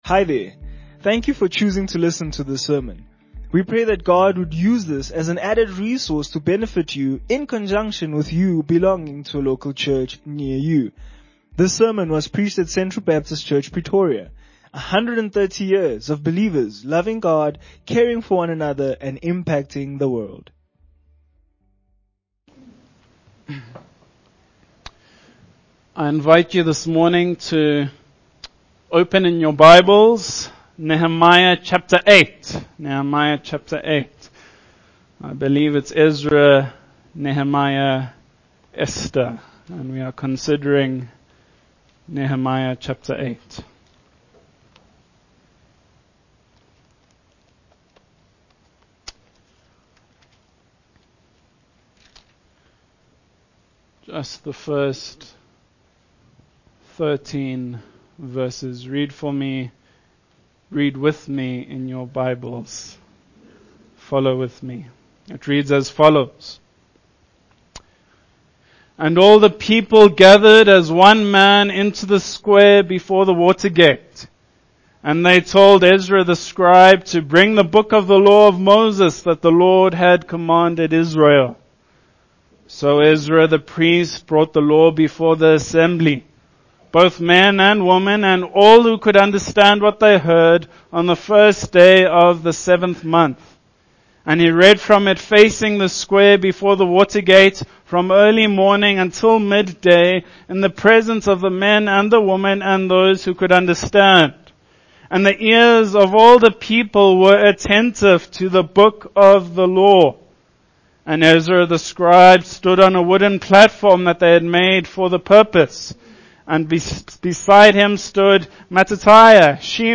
Sermons - Central Baptist Church Pretoria